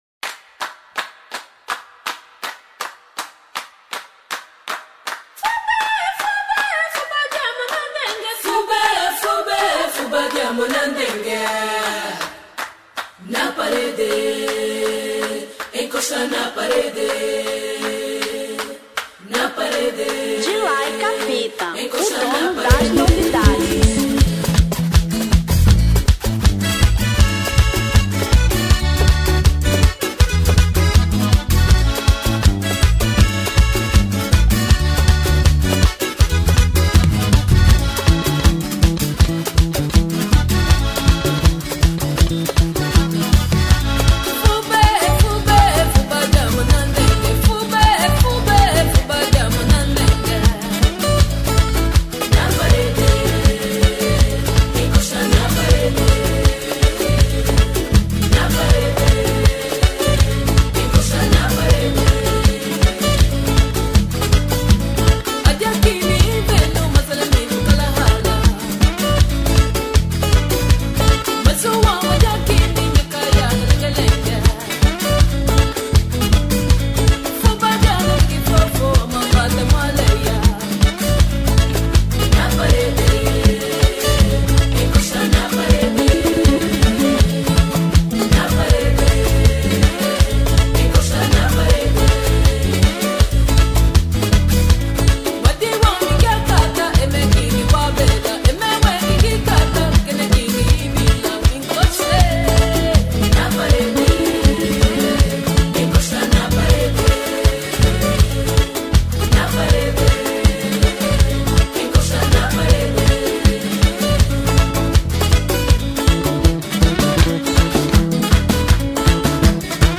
Semba 2000